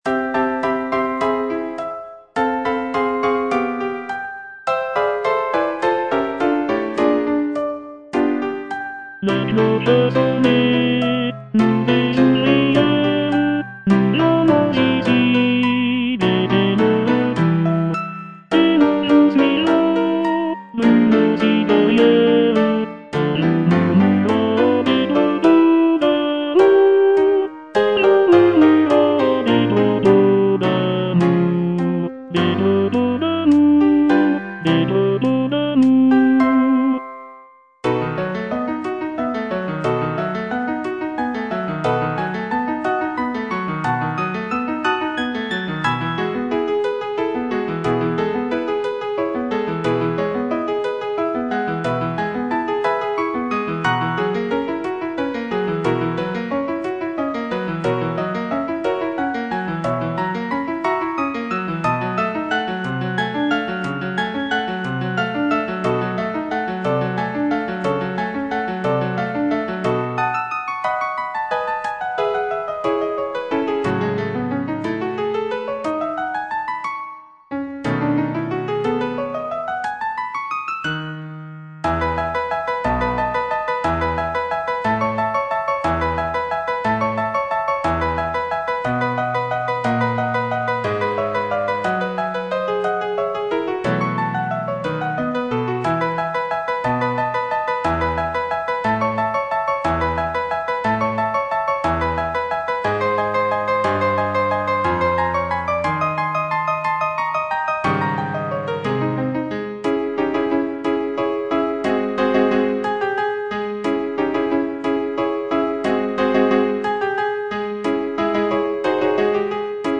G. BIZET - CHOIRS FROM "CARMEN" Chorus of cigarette-girls - Tenor (Voice with metronome) Ads stop: auto-stop